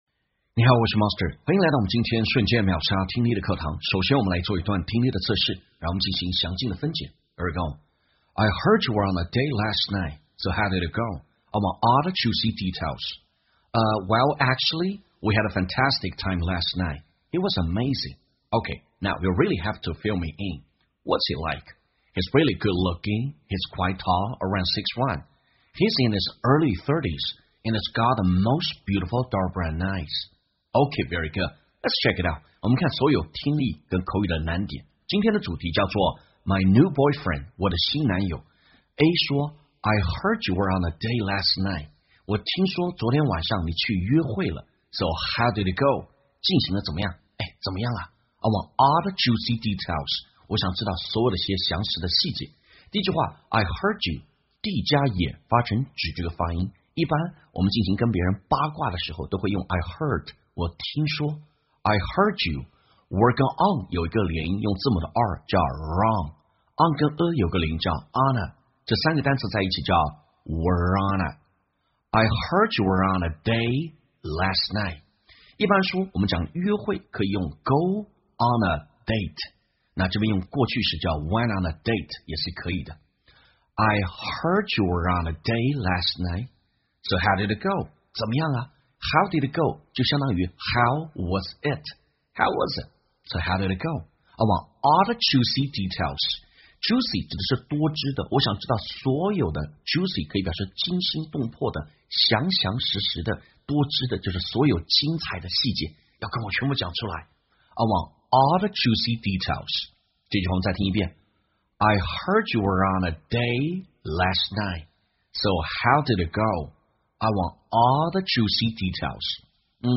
在线英语听力室瞬间秒杀听力 第646期:My New Boyfriend我的新男友的听力文件下载,栏目通过对几个小短句的断句停顿、语音语调连读分析，帮你掌握地道英语的发音特点，让你的朗读更流畅自然。